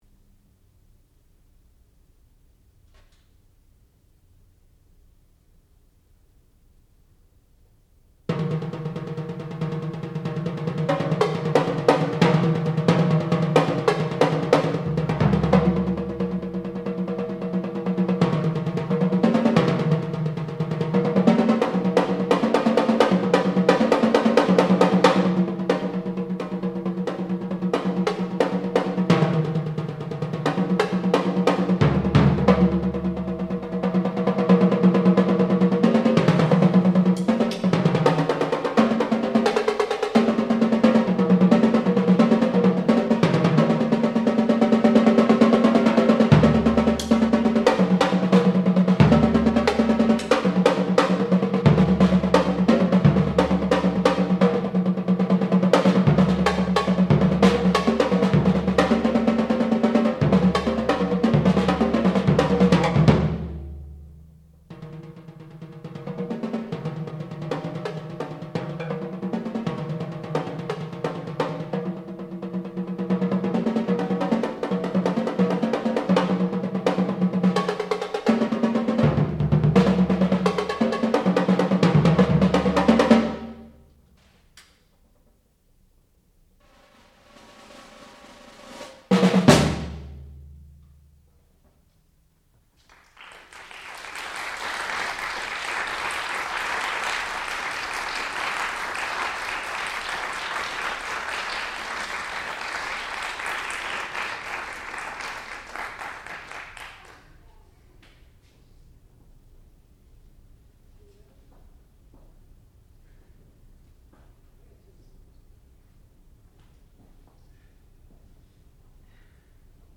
sound recording-musical
classical music
percussion
piano
Junior Recital